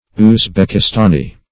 uzbekistani - definition of uzbekistani - synonyms, pronunciation, spelling from Free Dictionary
uzbekistani.mp3